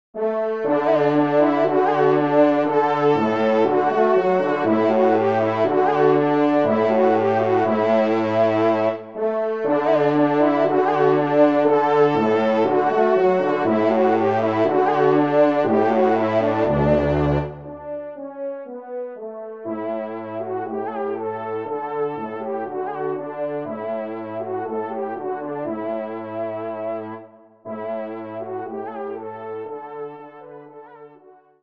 Genre : Divertissement pour Trompes ou Cors
Trompe Basse